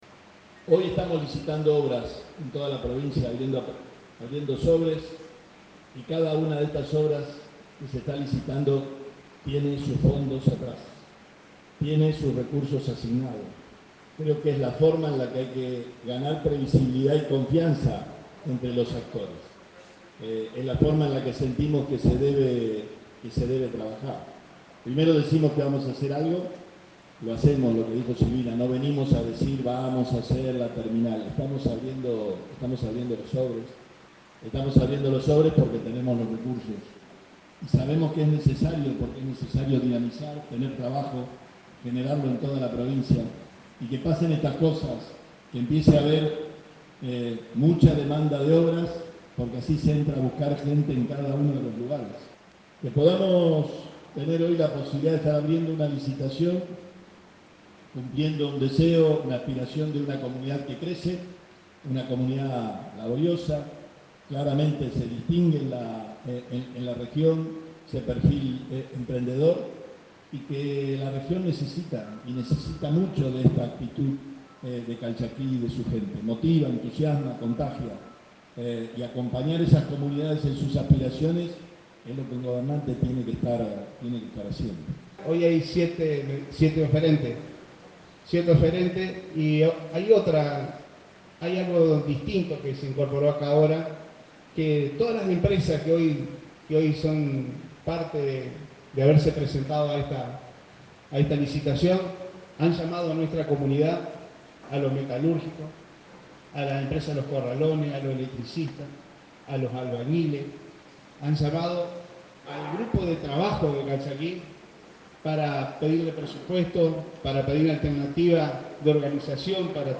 Gobernador Omar Perotti - Calchaquí